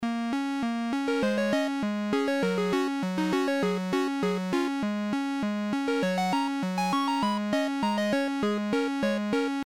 你好，8位方形合成器
Tag: 100 bpm Techno Loops Synth Loops 1.62 MB wav Key : Unknown